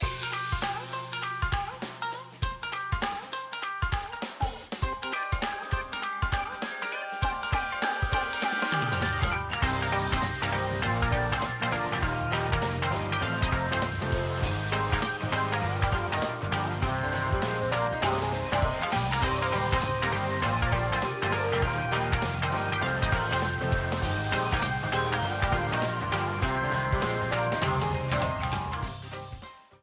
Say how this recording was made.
Ripped from the game clipped to 30 seconds and applied fade-out